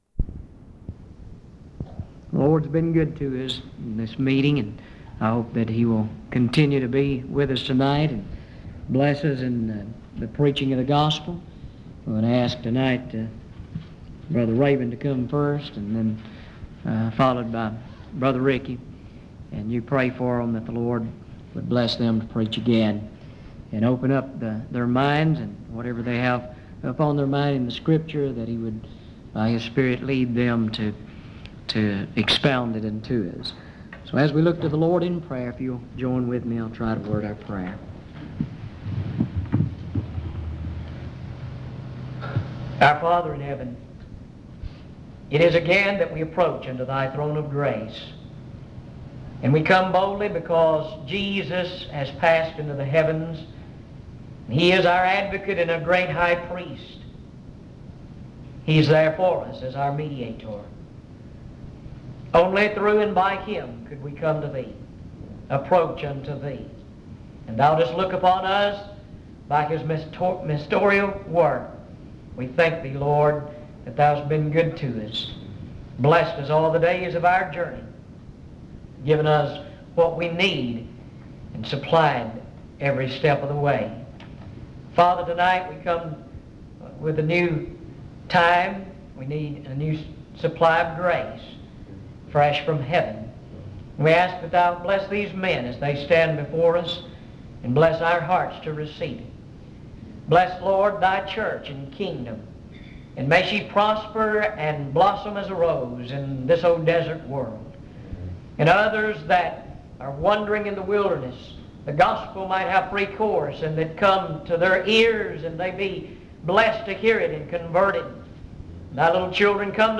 In Collection: Afton Road Primitive Baptist Church audio recordings Miniaturansicht Titel Hochladedatum Sichtbarkeit Aktionen PBHLA-ACC.003_014-B-01.wav 2026-02-12 Herunterladen PBHLA-ACC.003_014-A-01.wav 2026-02-12 Herunterladen